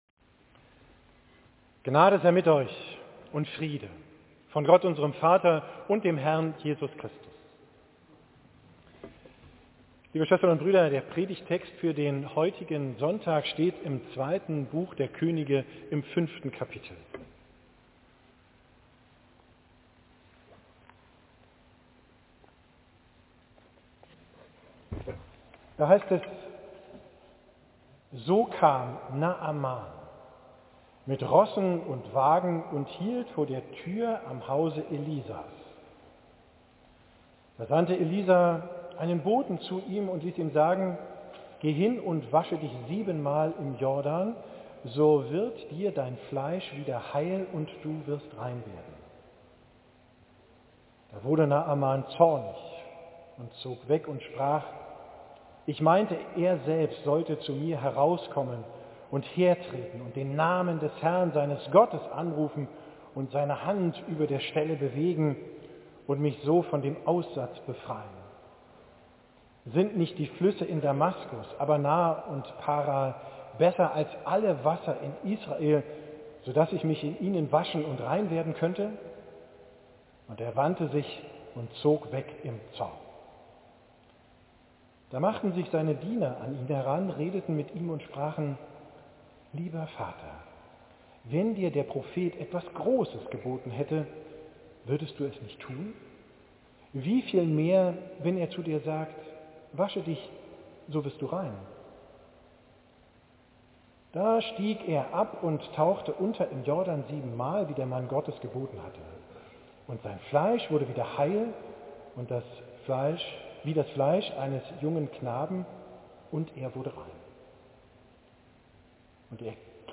Predigt vom 3.